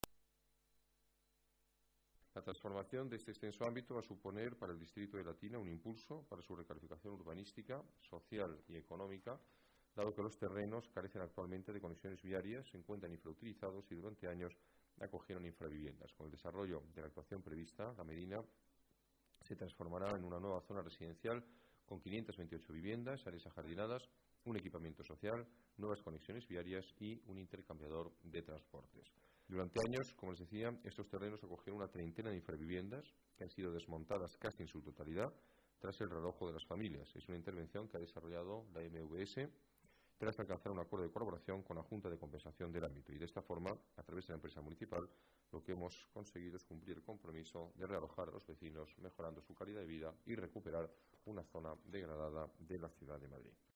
Nueva ventana:Declaraciones del alcalde Alberto Ruiz-Gallardón: renovación de La Medina